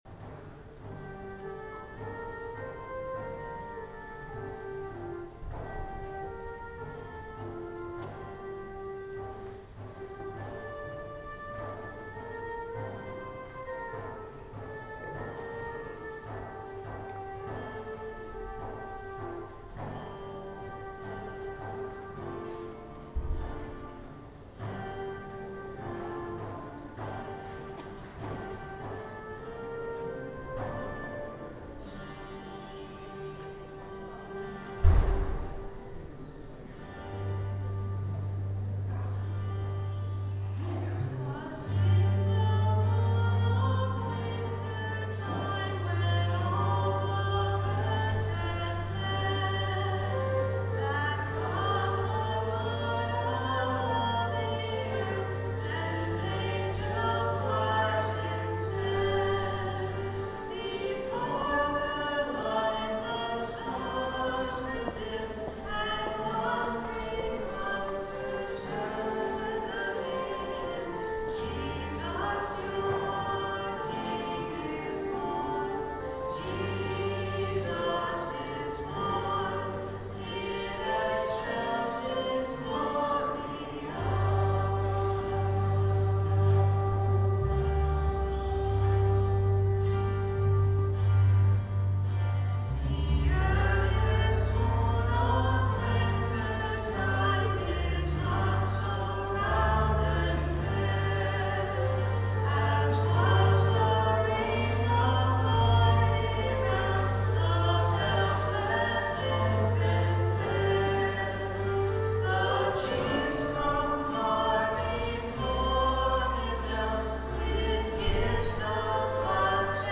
12/25/09 10:30 Mass Christmas Day Recording of Music - BK1030